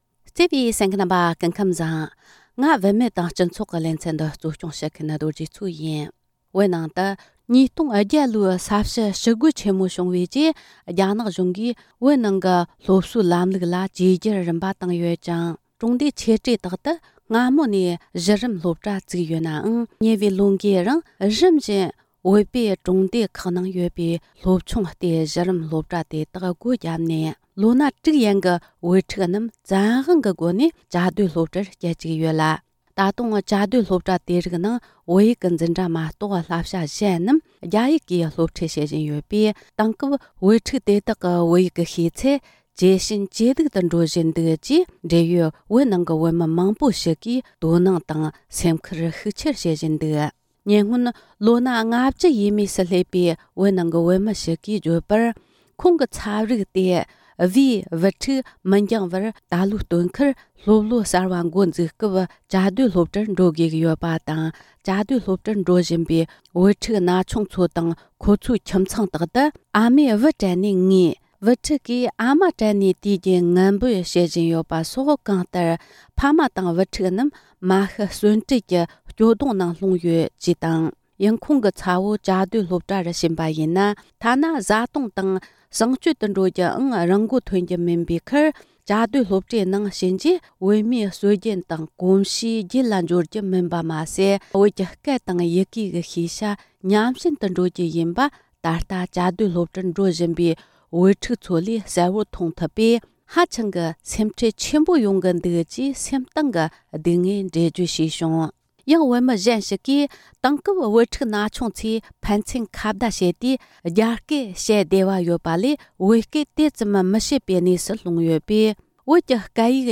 བཅར་འདྲི་ཞུས་ཏེ་གནས་ཚུལ་ཕྱོགས་སྒྲིག་བྱས་པར་གསན་རོགས་གནོངས།